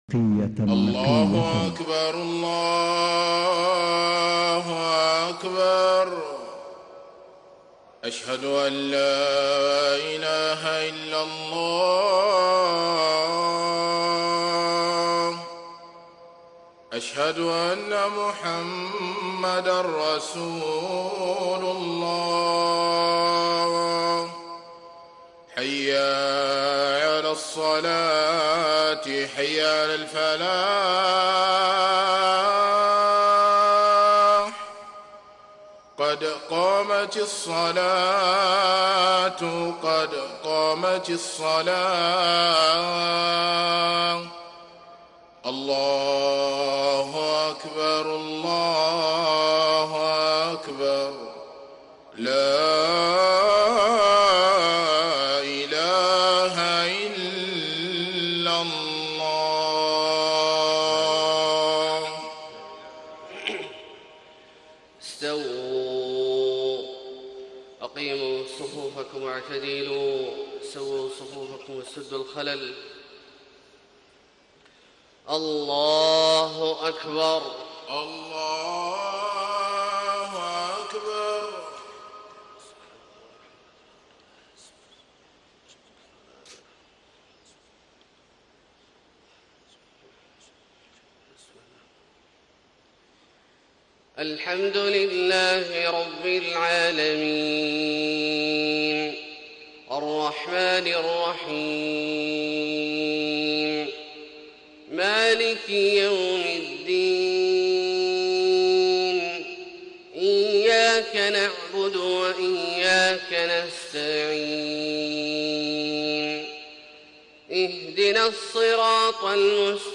صلاة الفجر 7 - 1 - 1435هـ من سورة فاطر > 1435 🕋 > الفروض - تلاوات الحرمين